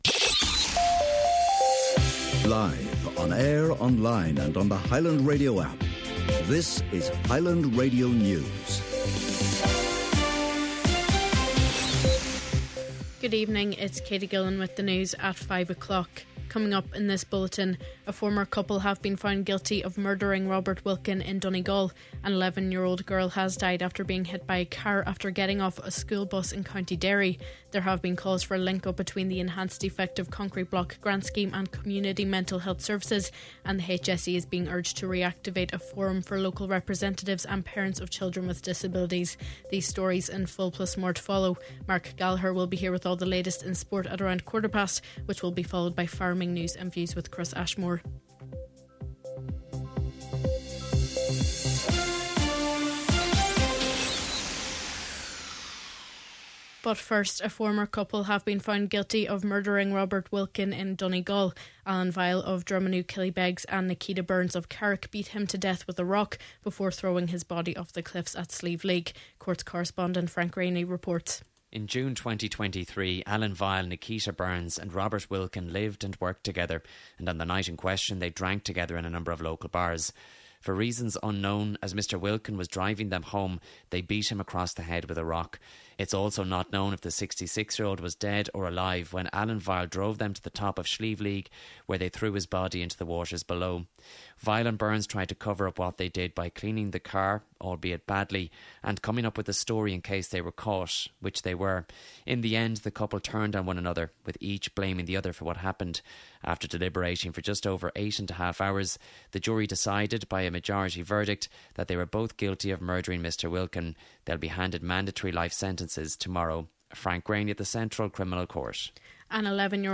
Main Evening News, Sport, Farming News & Views and Obituaries – Thursday March 6th